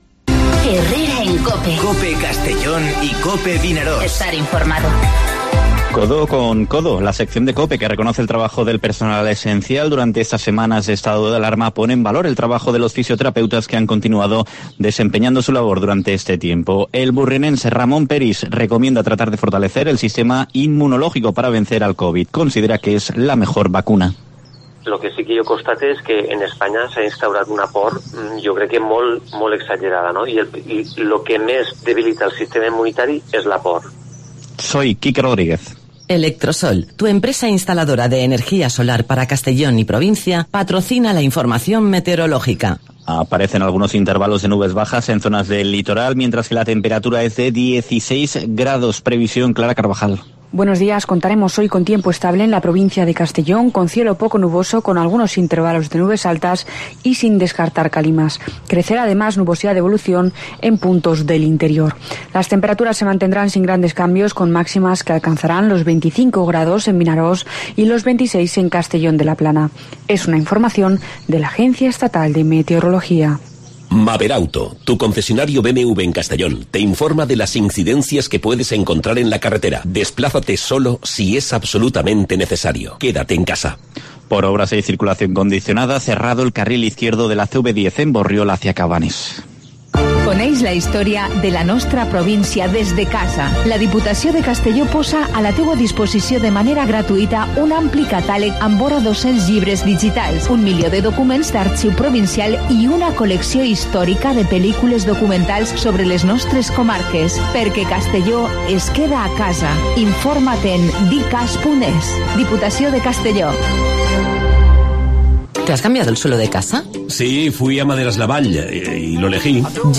Informativo Herrera en COPE en la provincia de Castellón (06/05/2020)